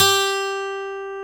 Index of /90_sSampleCDs/Roland L-CDX-01/GTR_Steel String/GTR_ 6 String
GTR 6-STR30Z.wav